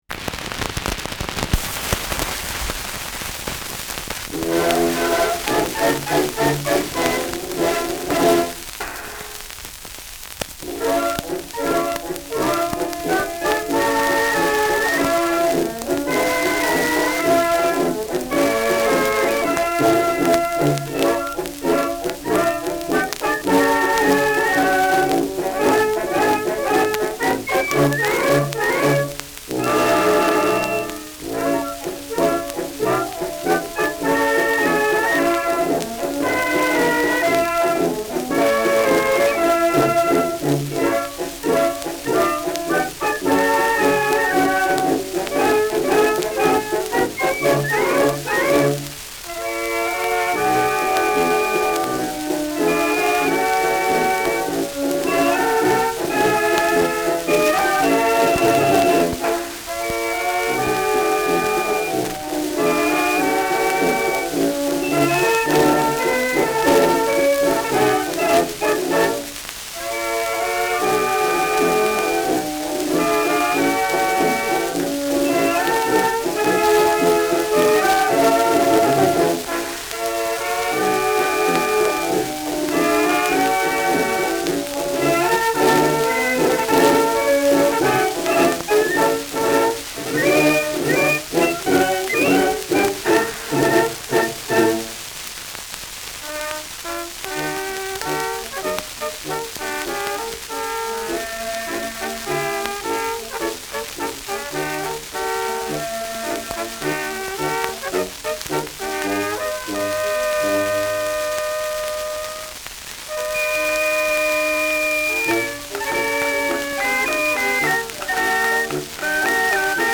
Schellackplatte
Juxmarsch* FVS-00002